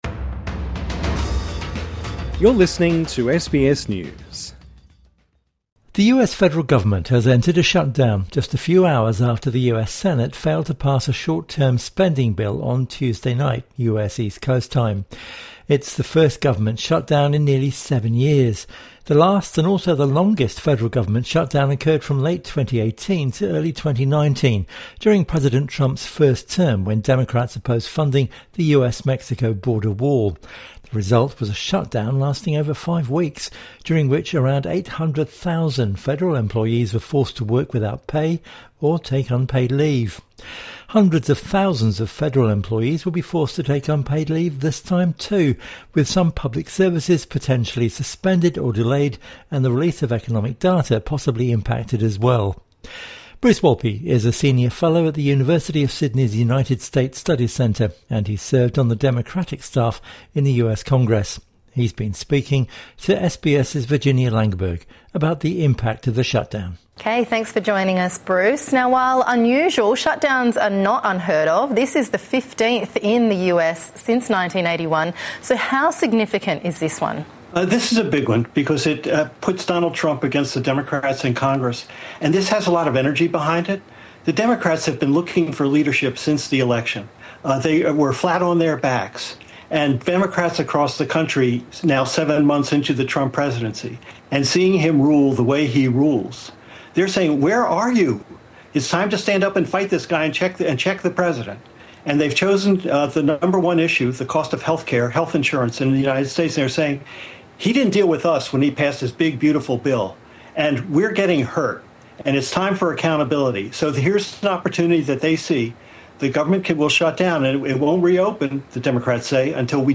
INTERVIEW: What does the US shutdown mean in practice?